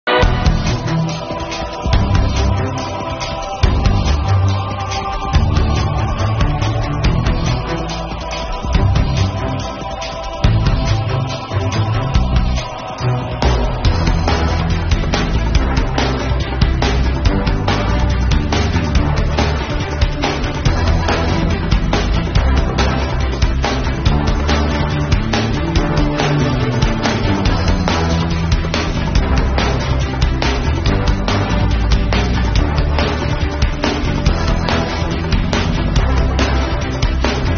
一声清脆的汽笛声响起